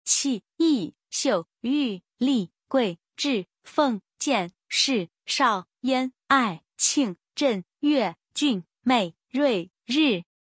第四声の漢字と発音